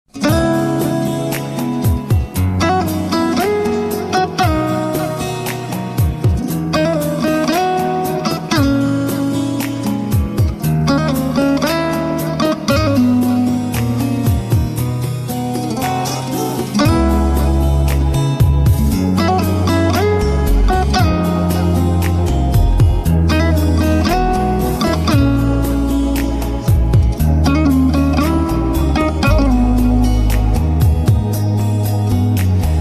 Мелодия в ритме румбы Ура!